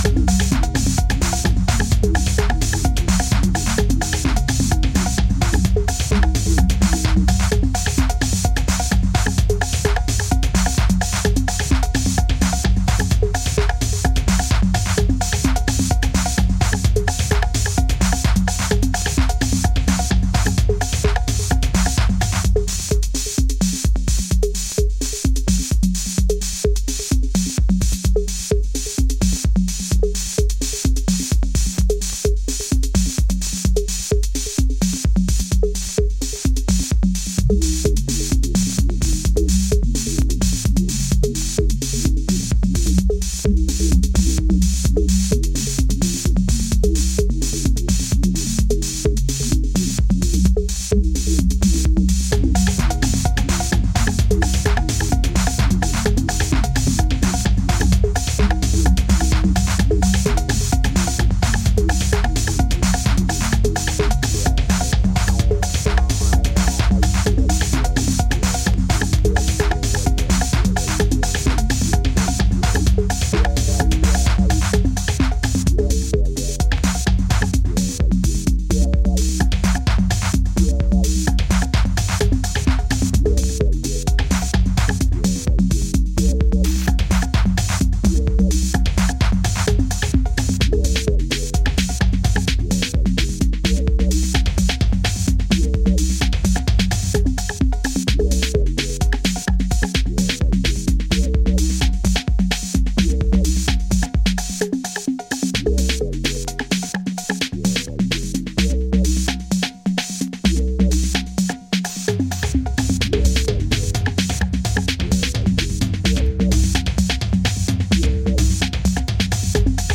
Used Electro Techno